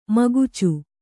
♪ magucu